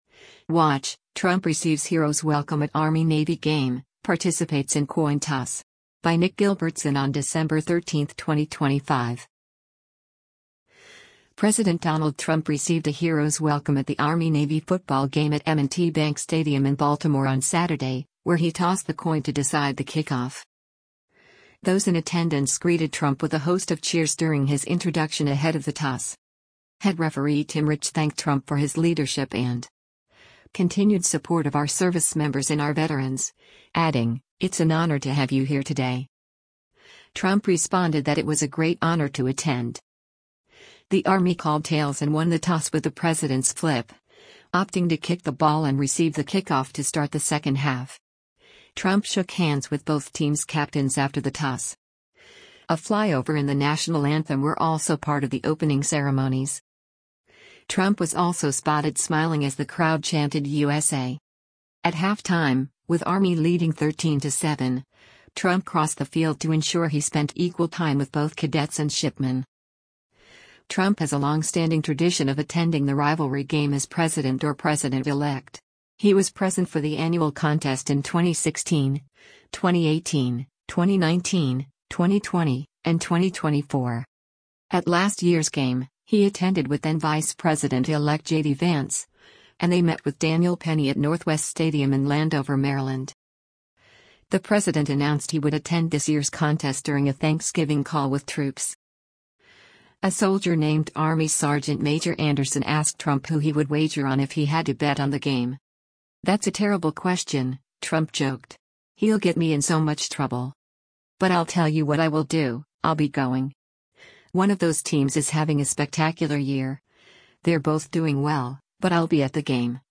President Donald Trump received a hero’s welcome at the Army-Navy football game at M&T Bank Stadium in Baltimore on Saturday, where he tossed the coin to decide the kickoff.
Those in attendance greeted Trump with a host of cheers during his introduction ahead of the toss.
Trump was also spotted smiling as the crowd chanted “USA!”